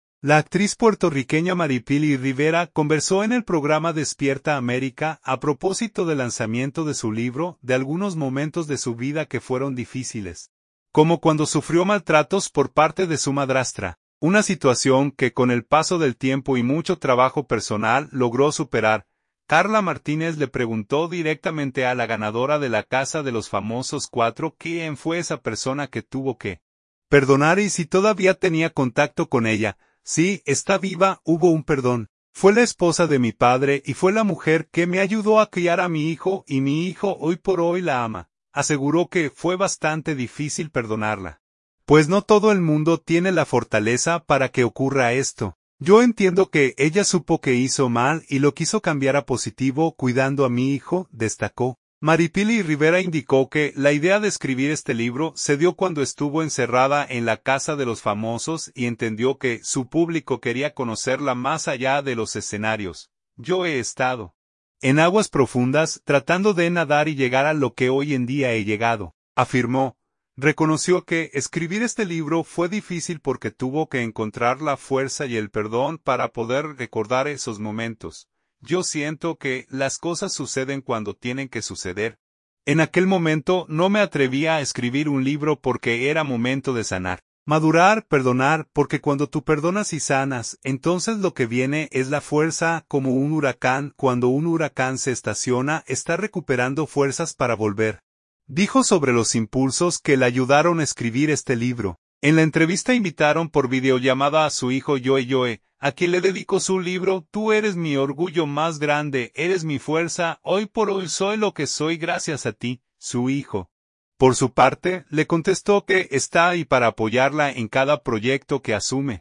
La actriz puertorriqueña Maripily Rivera conversó en el programa Despierta América, a propósito del lanzamiento de su libro, de algunos momentos de su vida que fueron difíciles, como cuando sufrió maltratos por parte de su madrastra, una situación que con el paso del tiempo y mucho trabajo personal logró superar.